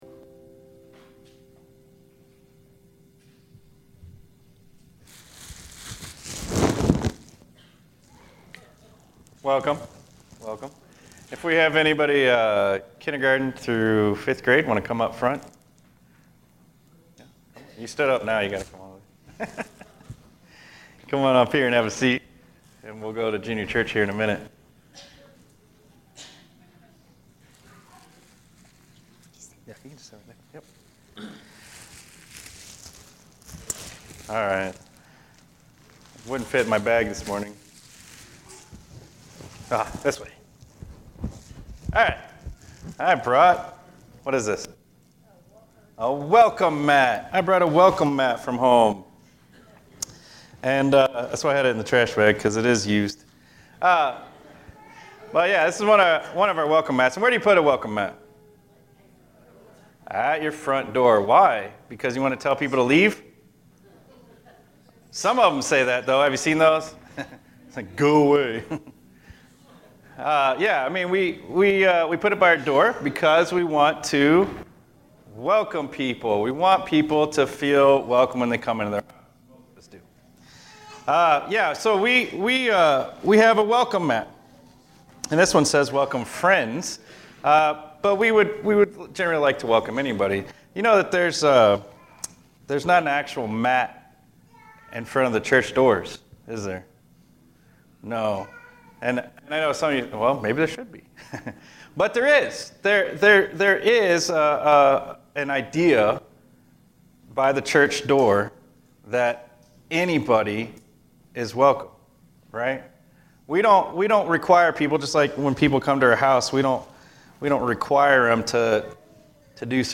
SUMMER SERMON SERIES, beginning 6/2/24